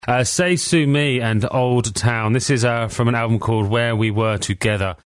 セイ・スー・ミー　（※韓国語ではセイスミーに近い）
故John Peelの息子、BBC Radio 6 の名物DJである Tom Ravenscroft の発音